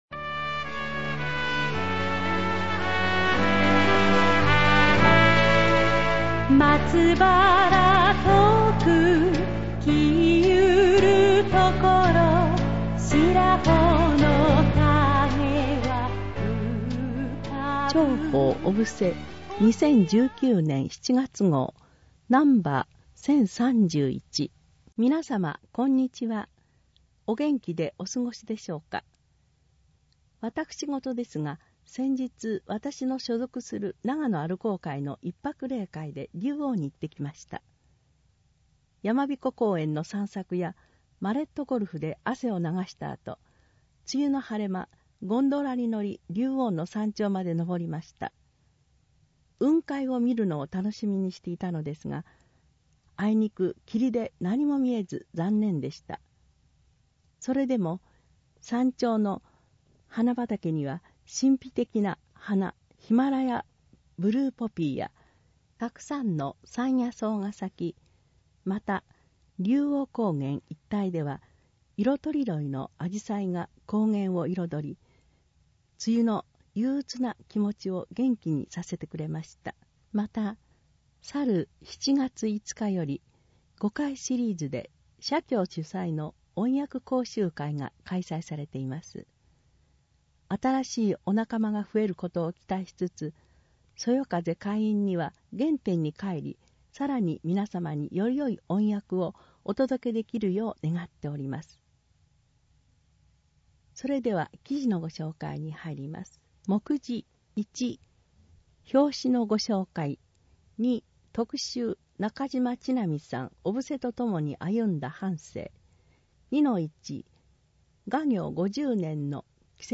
音訳は、ボランティアグループ そよ風の会の皆さんです。